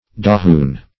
Meaning of dahoon. dahoon synonyms, pronunciation, spelling and more from Free Dictionary.
Search Result for " dahoon" : The Collaborative International Dictionary of English v.0.48: Dahoon \Da*hoon"\ (d[.a]*h[=oo]n"), [Origin unknown.]